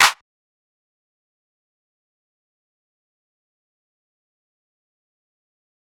Clap (West$ide).wav